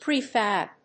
/prìːfˈæb(米国英語), ˈpri:ˈfæb(英国英語)/